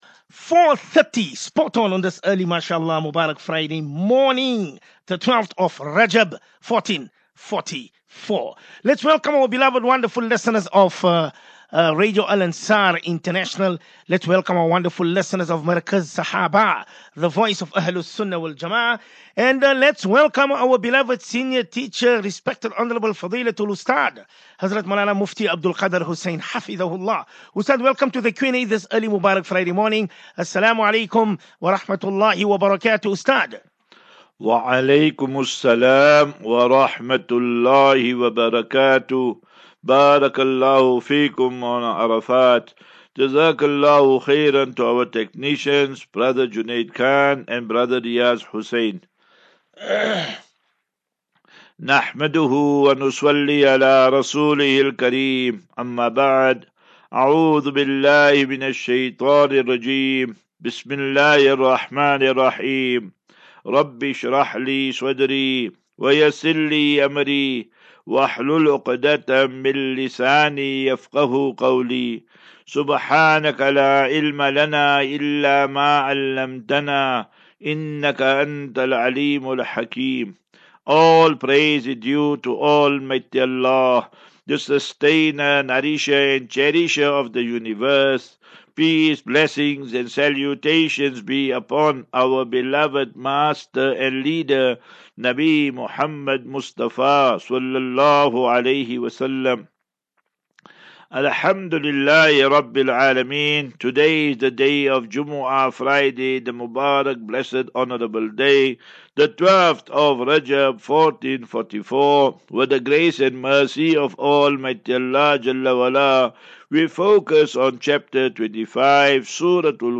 View Promo Continue Install As Safinatu Ilal Jannah Naseeha and Q and A 3 Feb 03 Feb 23- Assafinatu-Illal Jannah 37 MIN Download